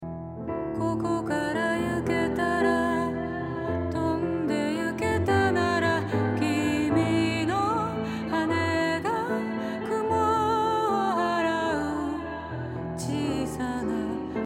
これによってディレイ音にもリバーブがかかりディレイ音の部分にも空間ができてより幻想的なイメージを作ることができるでしょう。